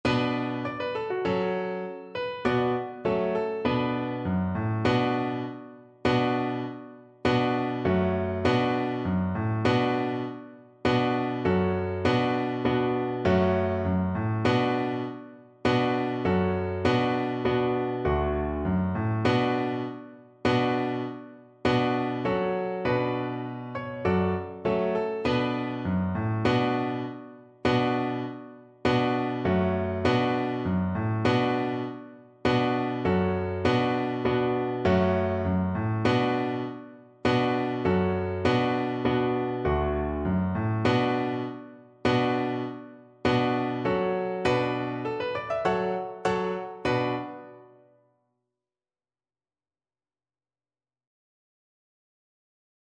Play (or use space bar on your keyboard) Pause Music Playalong - Piano Accompaniment Playalong Band Accompaniment not yet available transpose reset tempo print settings full screen
B minor (Sounding Pitch) (View more B minor Music for Cello )
Firmly =c.100
2/4 (View more 2/4 Music)